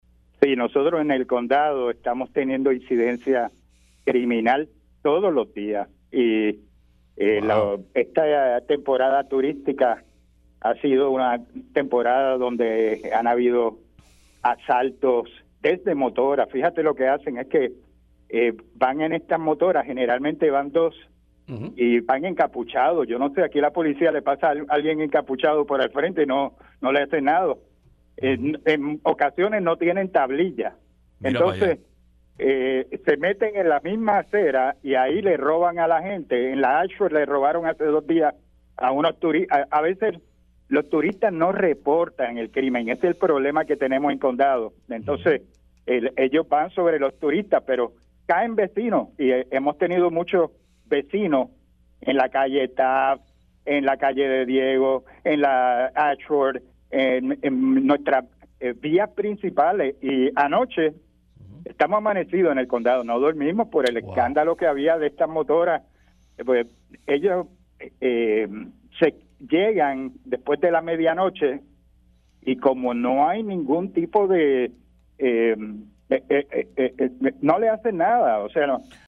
entrevista para Dígame la Verdad